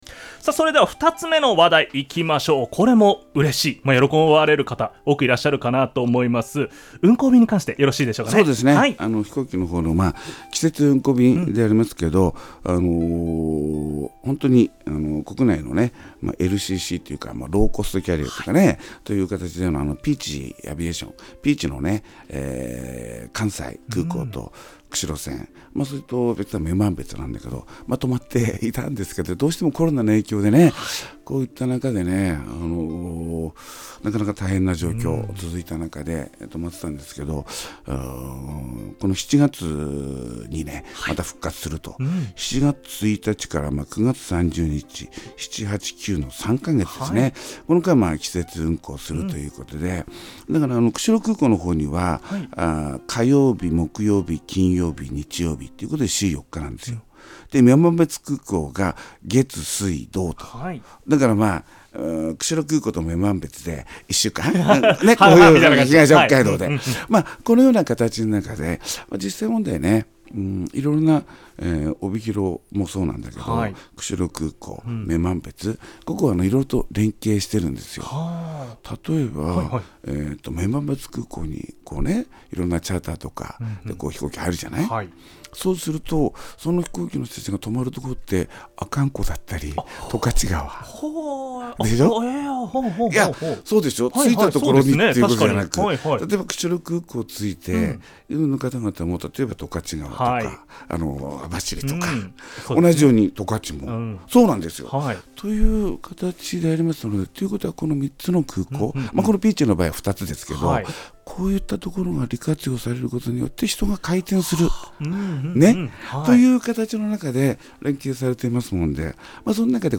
市長が出演した番組を掲載しています